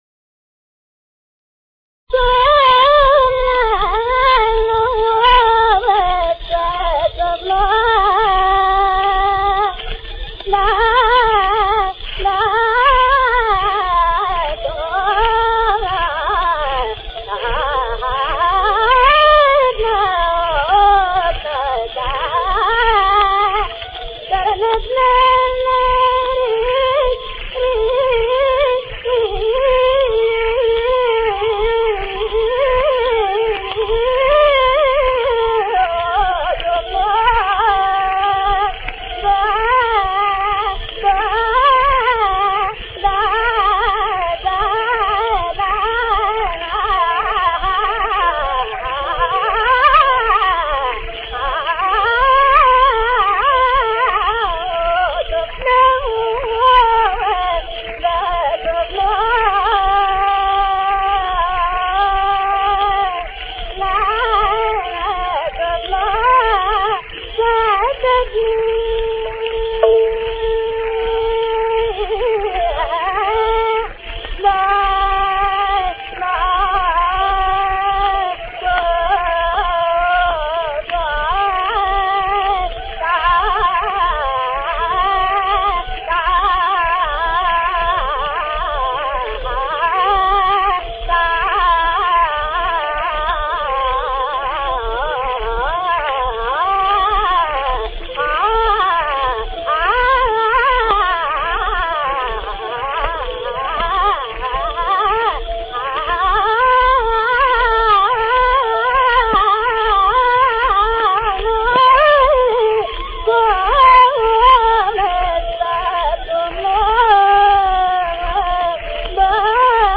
aalaapana.mp3